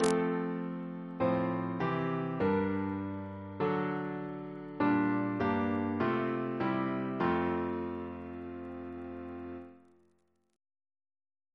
Single chant in F Composer: Ray Francis Brown (1897-1965) Reference psalters: ACP: 218